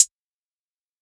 BWB VAULT HATS (Main).wav